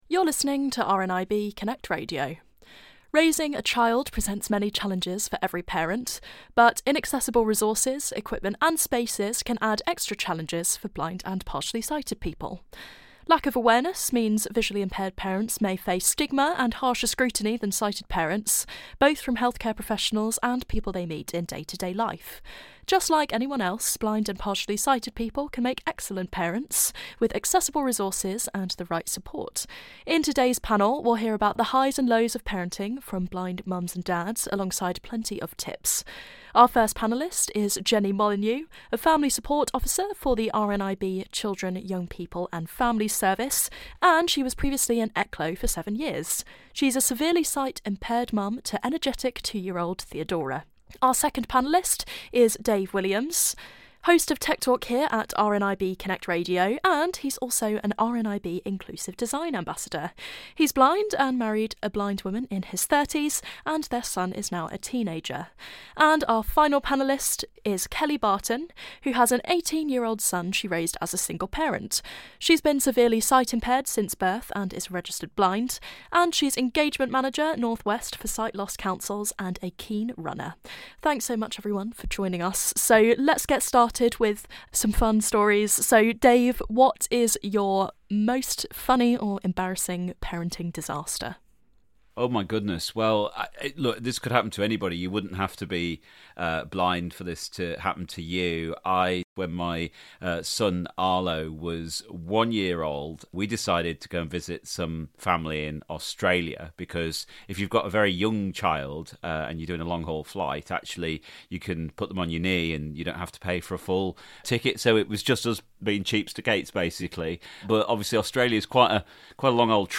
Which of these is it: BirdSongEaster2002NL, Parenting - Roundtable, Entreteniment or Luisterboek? Parenting - Roundtable